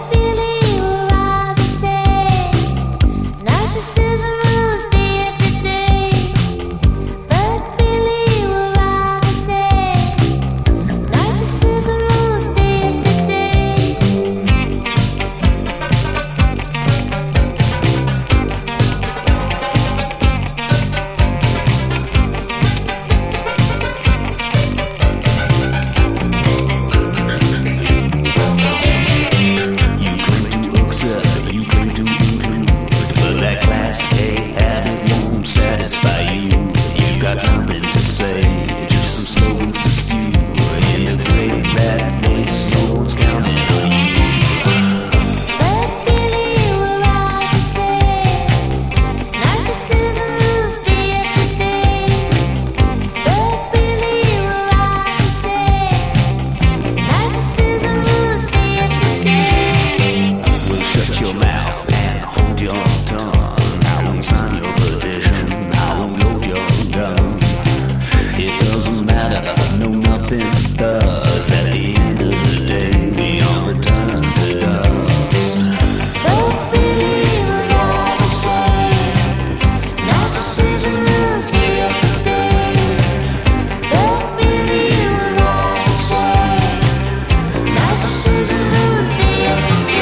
クセのあるボーカルが妖しく映えるファンキーなモダン・ディスコブギー。
FUNK / DEEP FUNK